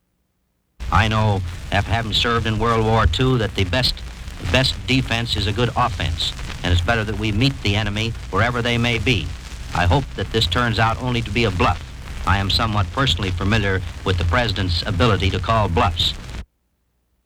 Broadcast 1950 July.